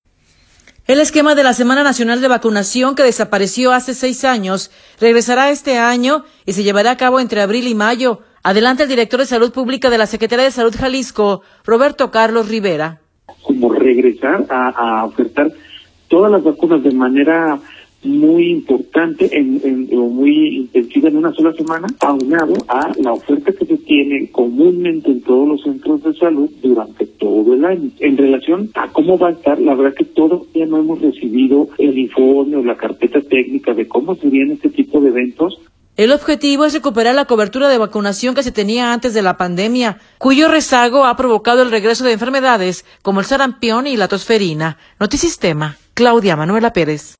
El esquema de la Semana Nacional de Vacunación que desapareció hace seis años, regresará este año y se llevaría a cabo entre abril y mayo, adelanta el director de Salud Pública de la Secretaría de Salud Jalisco, Roberto Carlos Rivera.